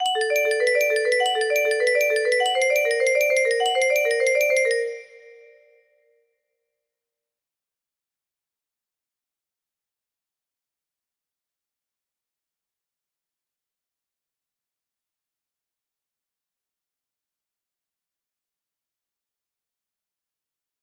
Yay! It looks like this melody can be played offline on a 30 note paper strip music box!